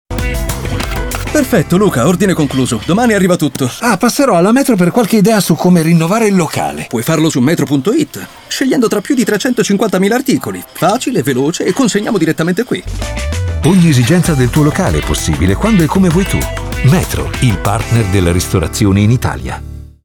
Spot Metro - Ogni esigenza del tuo locale
E’ uno degli Speaker Italiani fra i più ascoltati in televisione e in radio, particolarmente versatile l’abbiamo ascoltato sia per i toni “caldi” che per l’irresistibile freschezza e allegria.